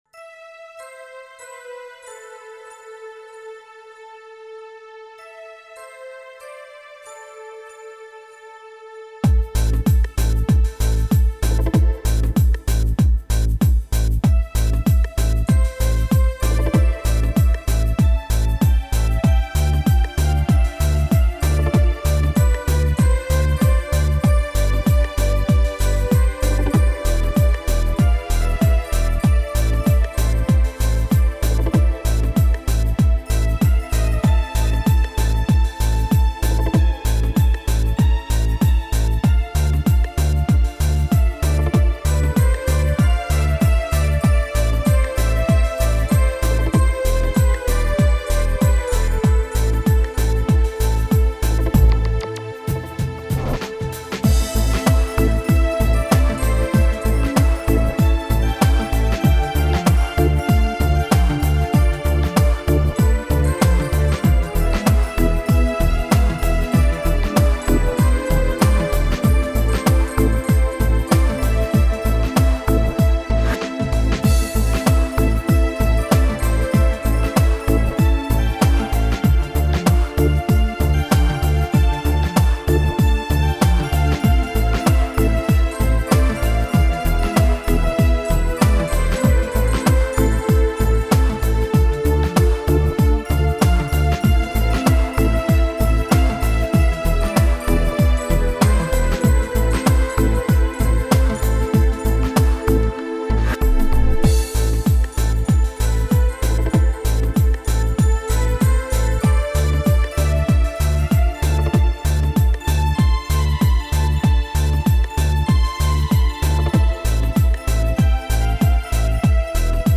Melodierne fejler for saa vidt ikke noget, men de er helt sikkert ikke godt arrangeret.